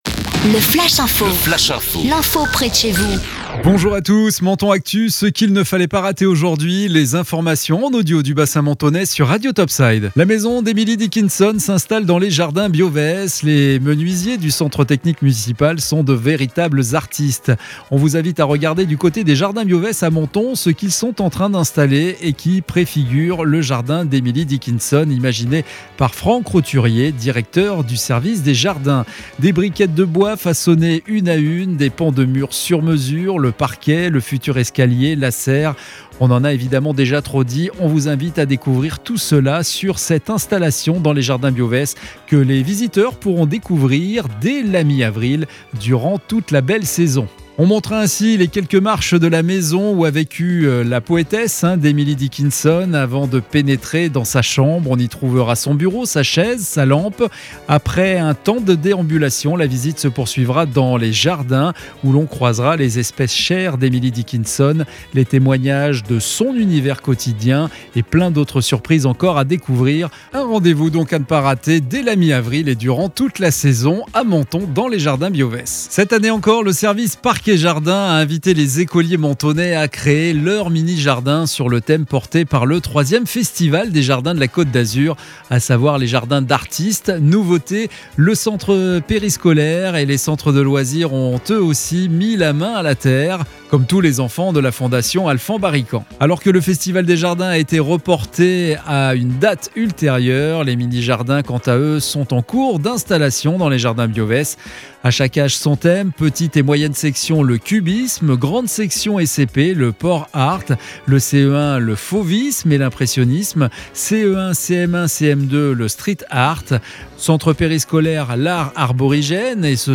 Menton Actu - Le flash info du lundi 12 avril 2021